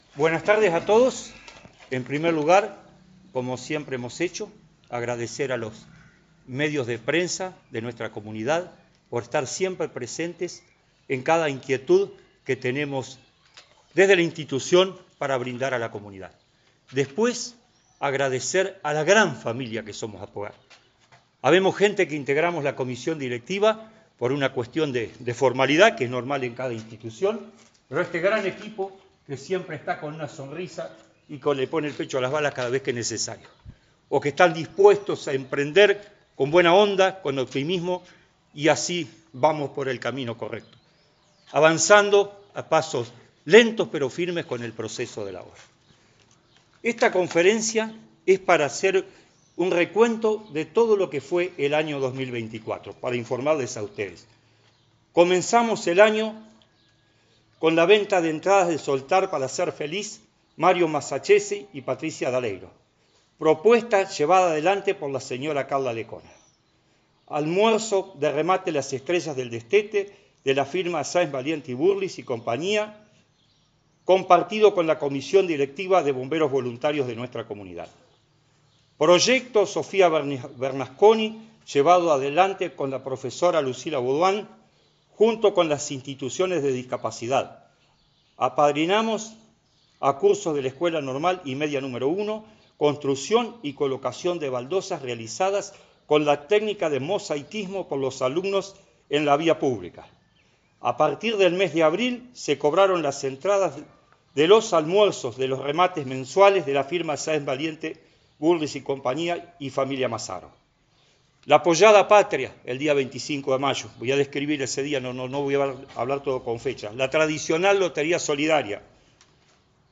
Convocada por la comisión directiva y colaboradores de Aphogard, en la tarde del miércoles, se llevó a cabo una conferencia de prensa en el predio que la institución posee en Av. Carramasa y Moreno.